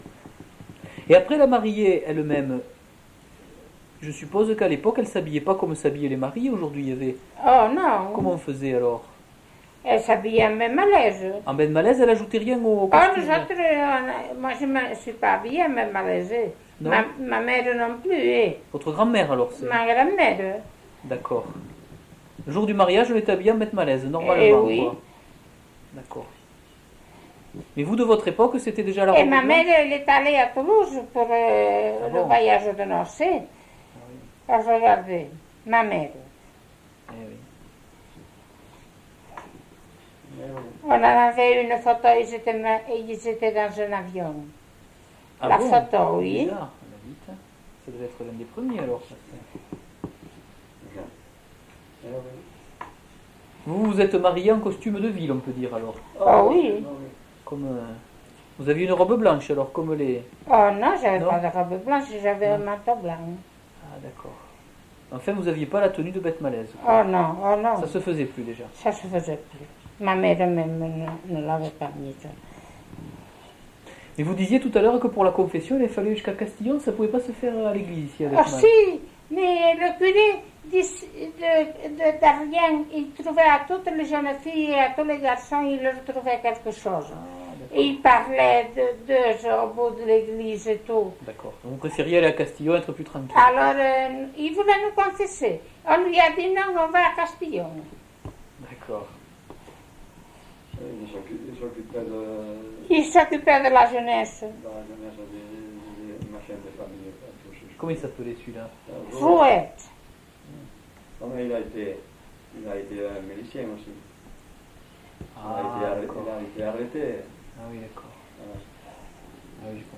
Aire culturelle : Couserans
Genre : témoignage thématique